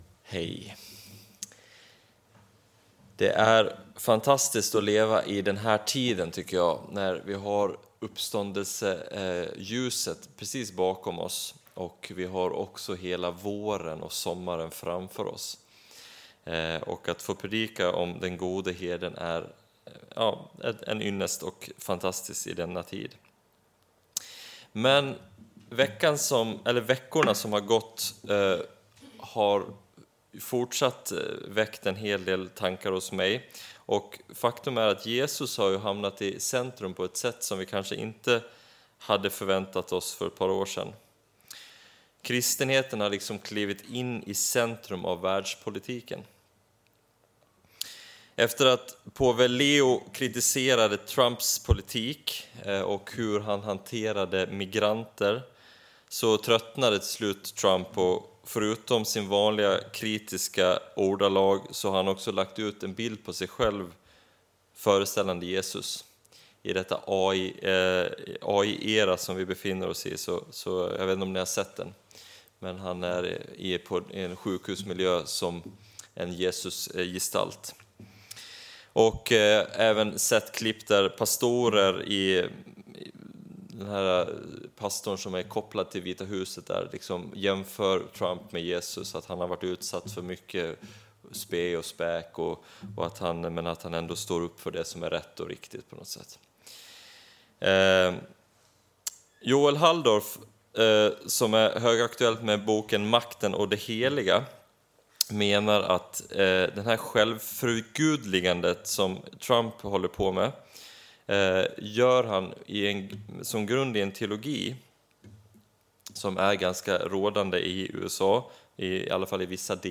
Inspelad under gudstjänst i Equmeniakyrkan Väte 2026-04-19.